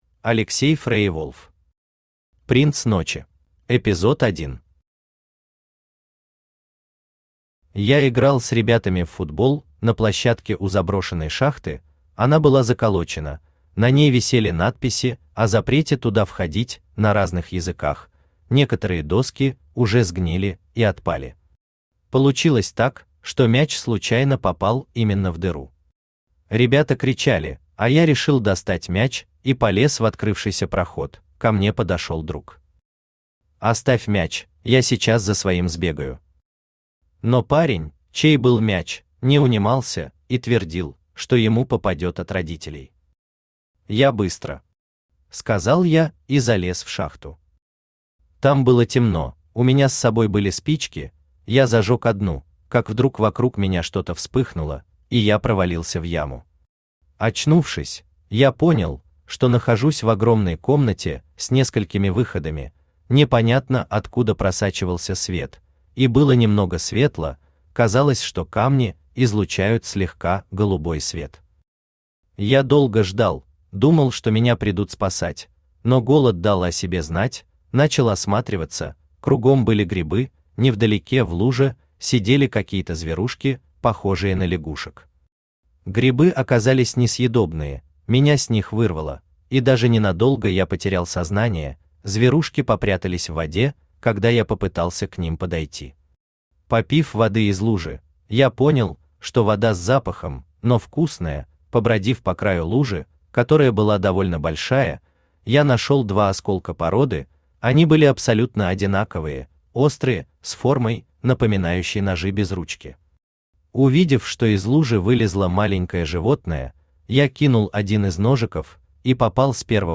Aудиокнига Принц ночи Автор Алексей Леонидович FreierWolf Читает аудиокнигу Авточтец ЛитРес.